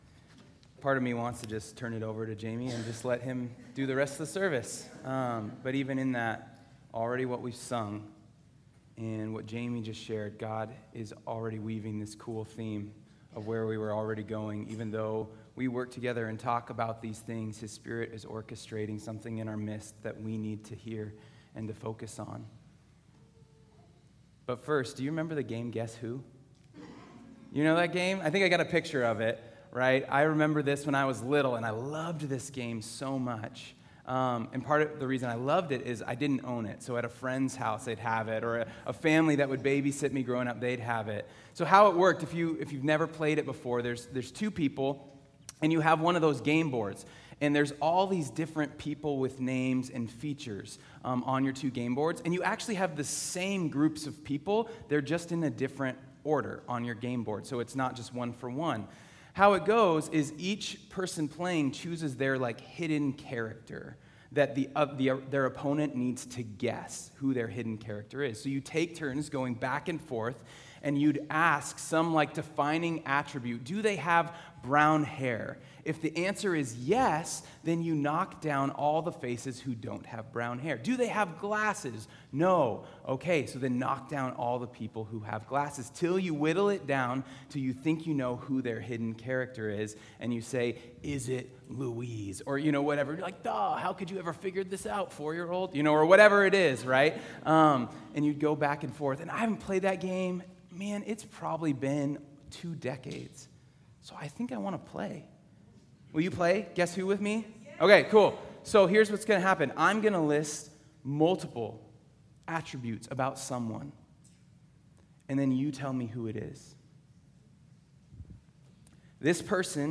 sermon_9_21_25.mp3